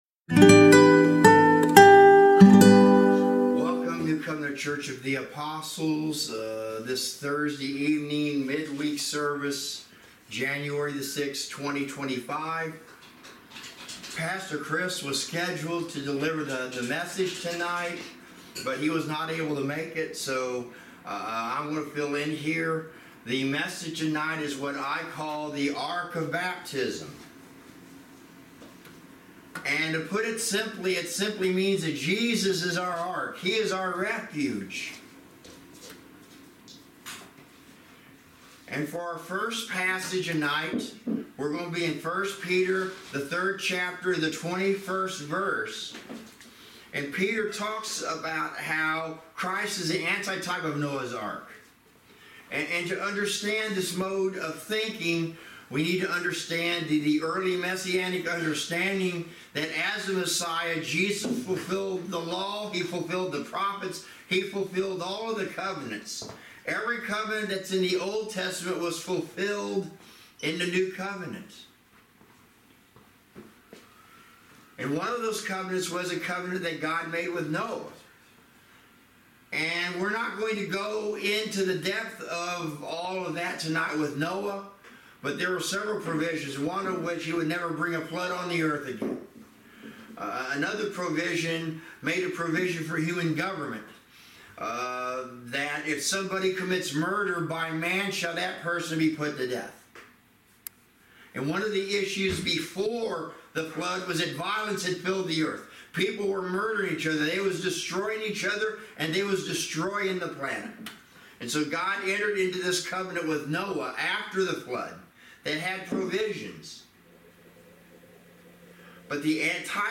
Midweek Teaching